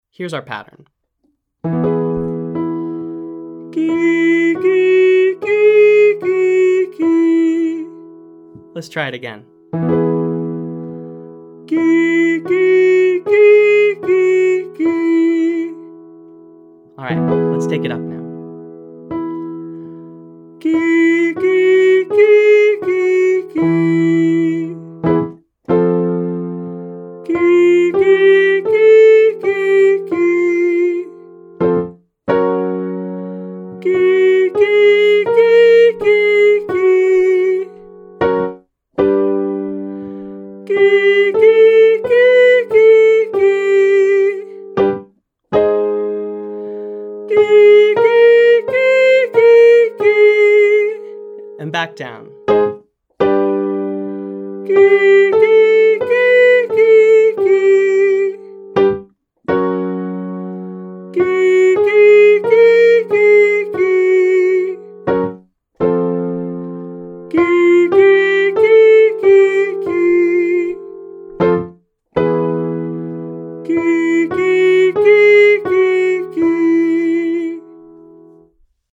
The exercise is just approximating, or getting close to, the pitch I play on the piano.